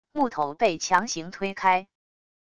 木头被强行推开wav音频